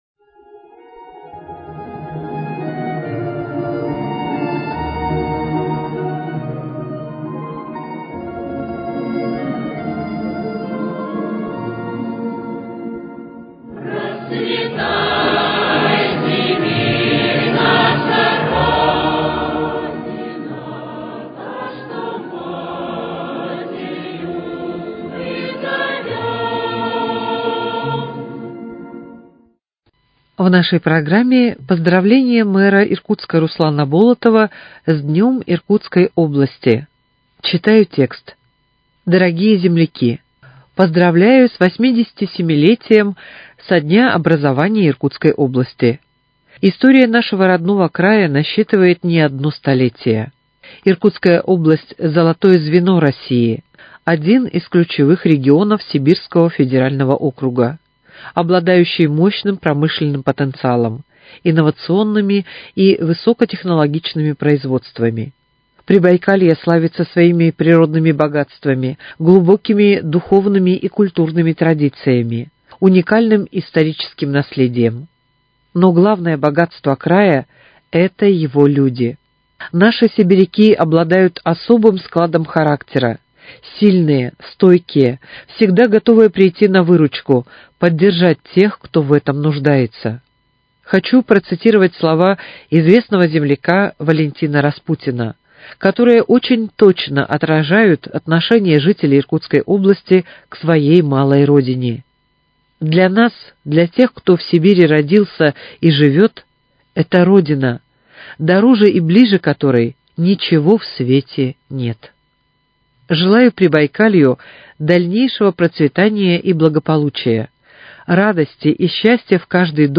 Поздравление мэра Руслана Болотова с Днём рождения Иркутской области